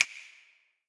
YM - Reverb Snap 5.wav